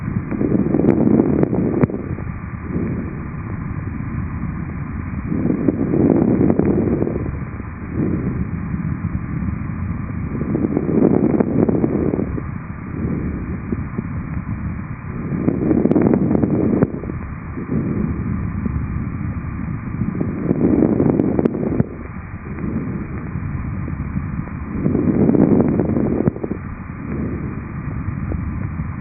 HENGITYSÄÄNET
Keuhkoverenkierron kuormituksen takia nestettä tihkuu soluvälitilasta keuhkorakkuloihin, aiheuttaen hengitysääninäytteessä kuultavan ritinän.